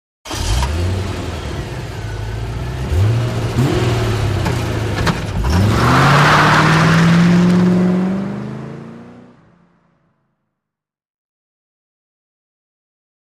Corvette; Start / Away Fast; Quick Start With Sharp Transmission Clicks, Rev And Fast Away With Grit Under Tires. Close To Distant Perspective. Sports Car, Auto.